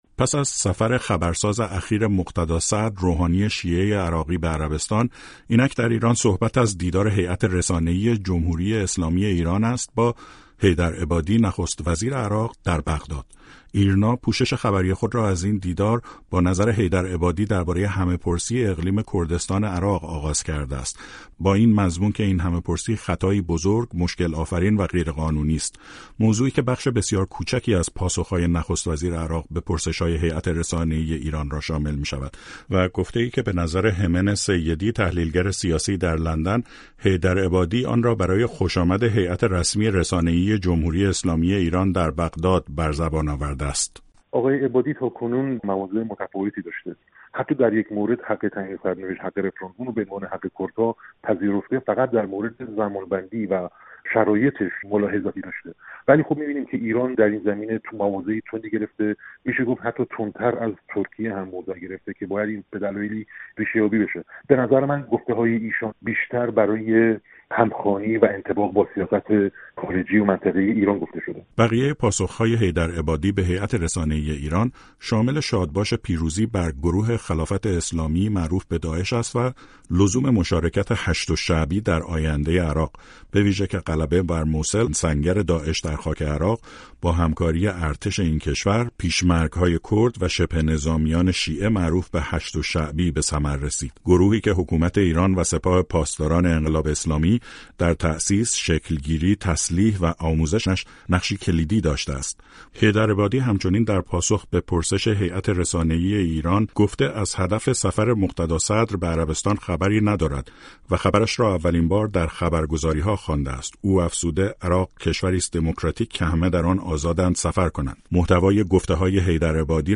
رادیو فردا نظر سه کارشناس را درباره حاصل این دو دیدار پرسیده است.